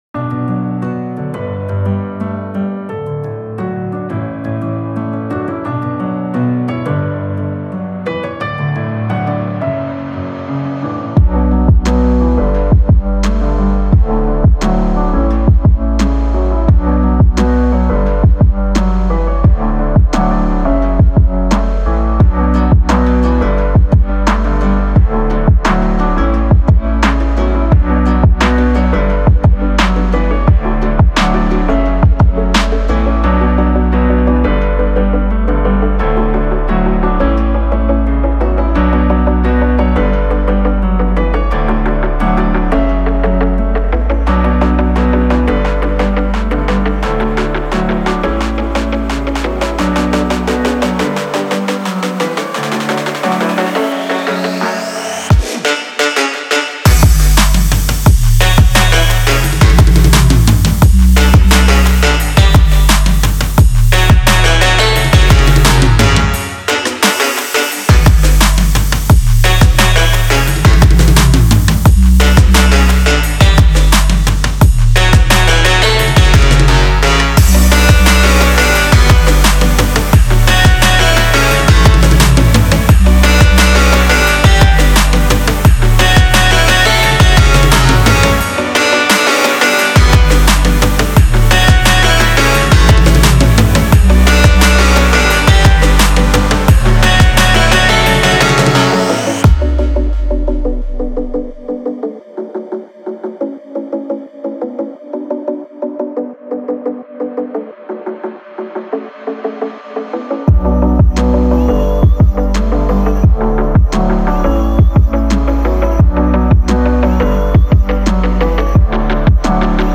Trap, Romantic, Quirky, Sexy, Hopeful